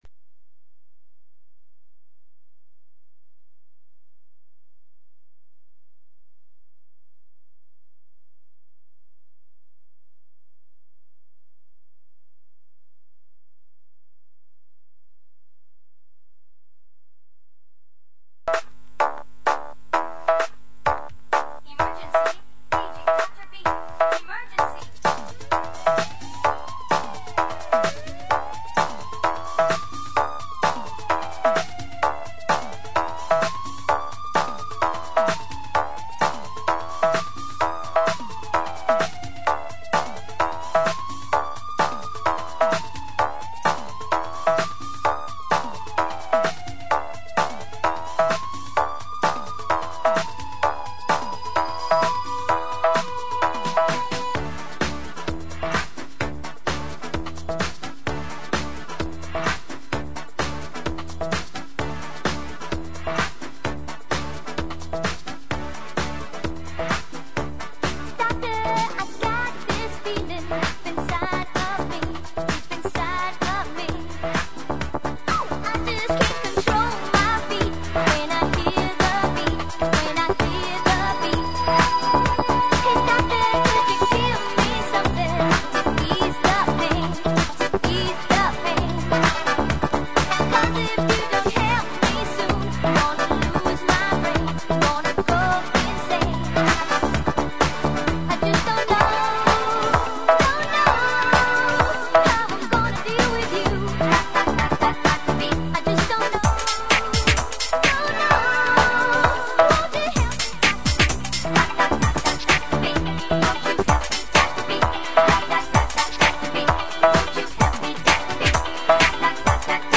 UK UNDERGROUND GARAGE MIX...